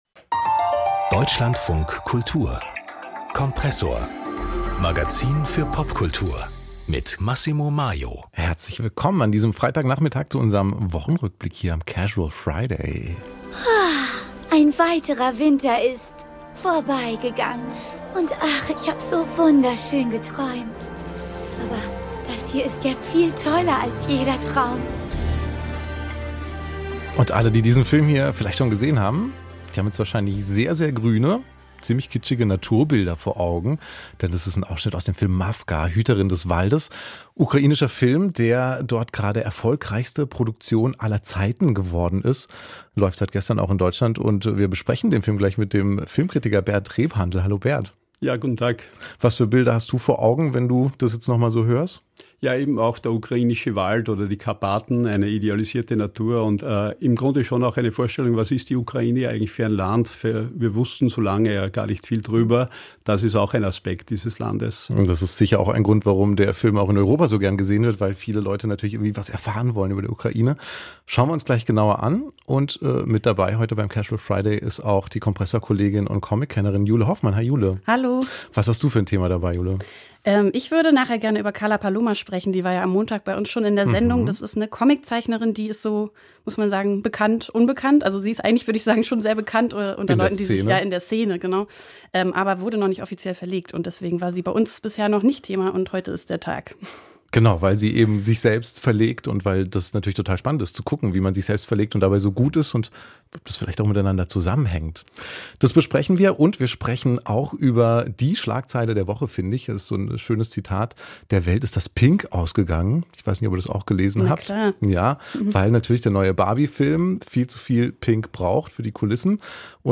Meinung & Debatte Archiv Popkultur-Wochentalk Ukrainischer Anime, Barbie-Hype & Insta-Comics 40:50 Minuten Der Kinofilm um die wohl berühmteste Puppe der Welt startet am 21.